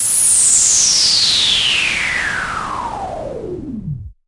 太空小说音效 " 07021近距离太空飞行器开车通过
描述：关闭futurisic车辆通过
标签： 未来 宇宙飞船 关闭 车辆 传球 摩托车 空间 驾车通过 科幻 传递由
声道立体声